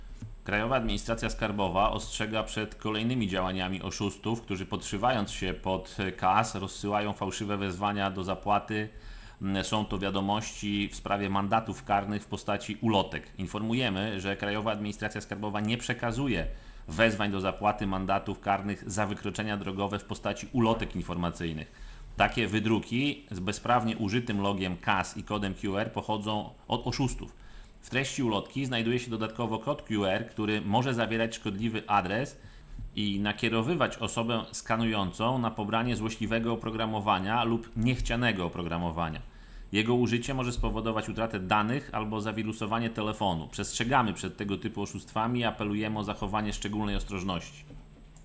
Fałszywe wezwania do zapłaty (wypowiedź